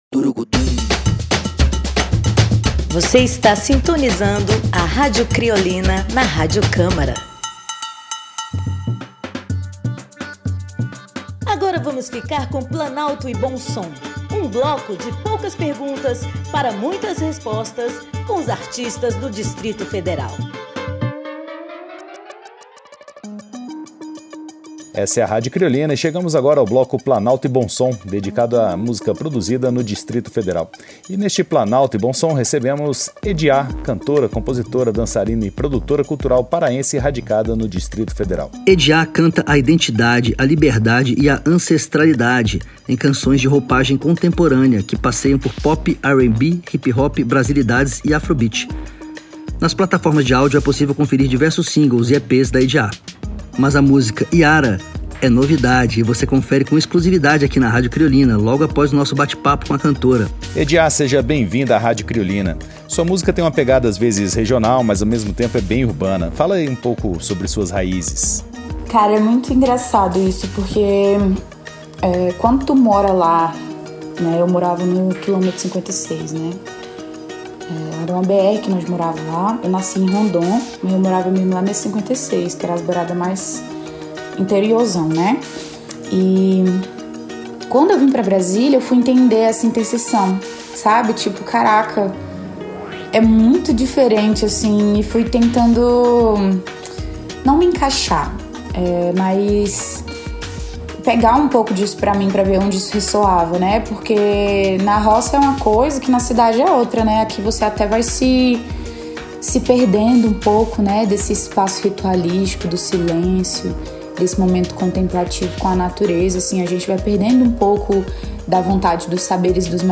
Música brasileira contemporânea, com entrevistas no bloco “Planalto e Bom Som”, que destaca a produção do Distrito Federal.